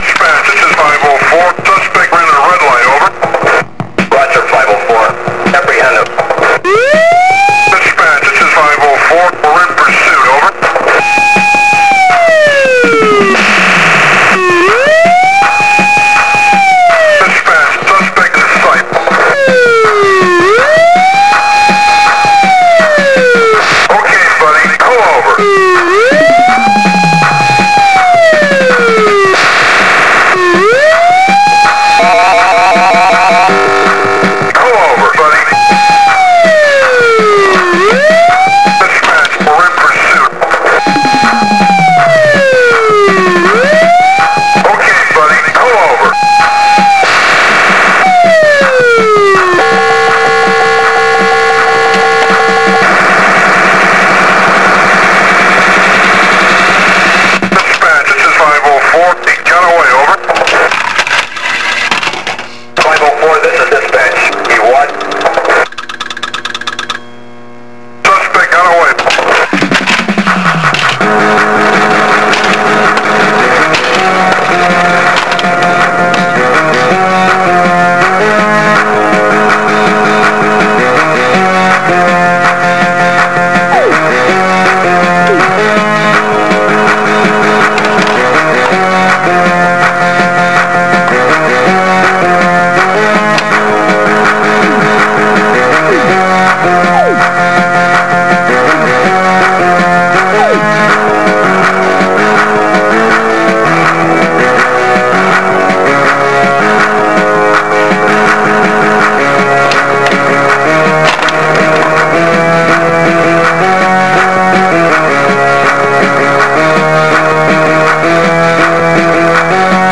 Download 2,54Mb Tema fra spillet